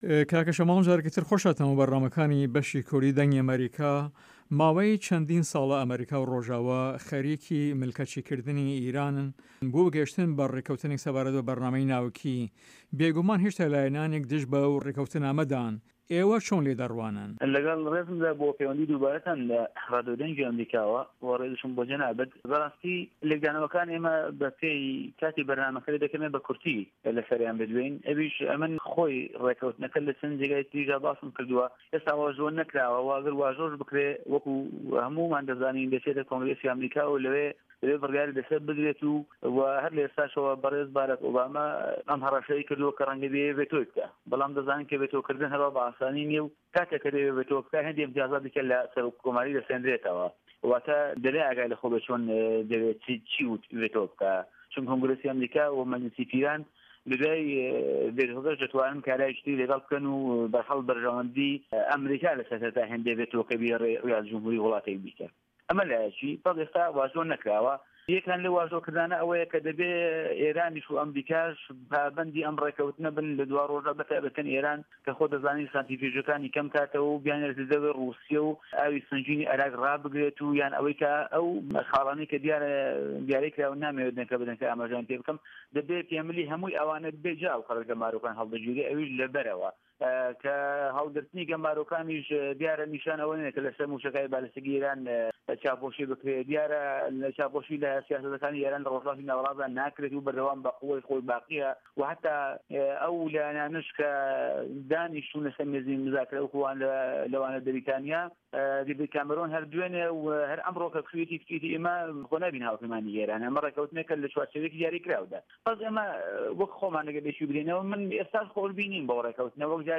له‌ هه‌ڤپه‌یڤینێکدا له‌گه‌ڵ به‌شی کوردی ده‌نگی ئه‌مه‌ریکا